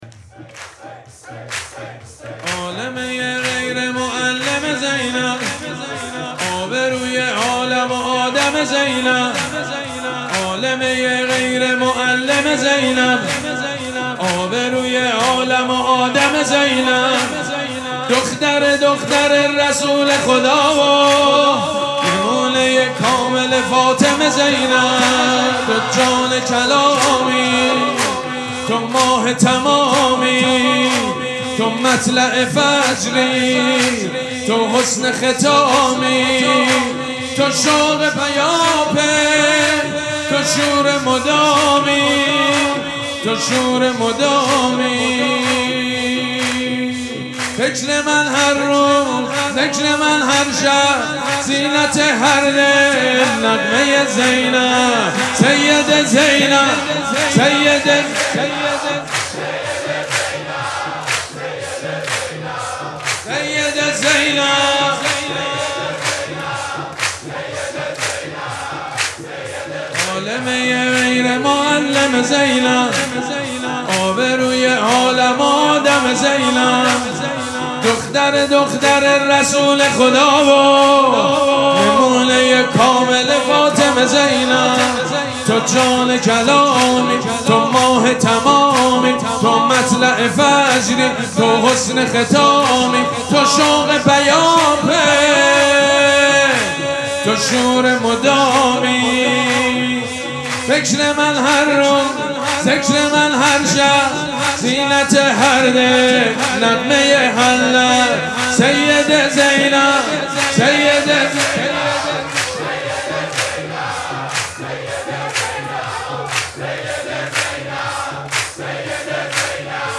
مراسم جشن ولادت حضرت زینب سلام‌الله‌علیها
حسینیه ریحانه الحسین سلام الله علیها
سرود